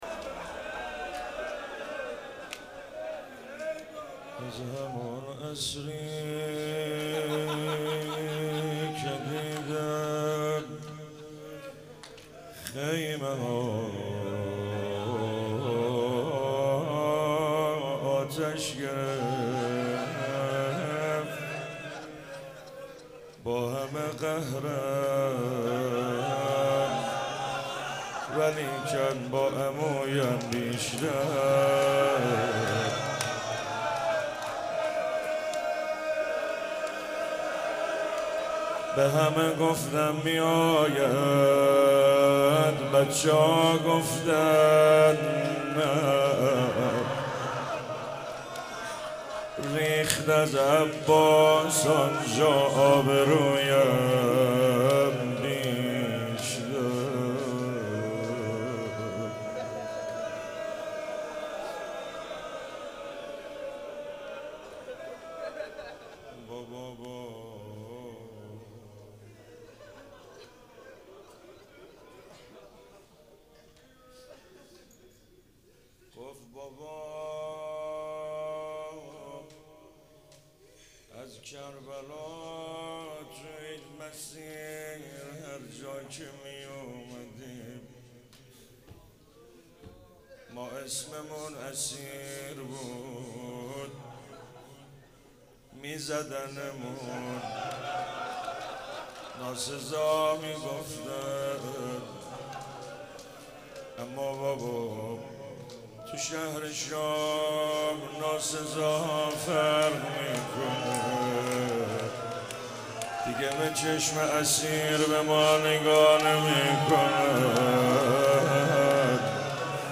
روضه - از همان عصری که دیده ام خیمه ها آتش گرت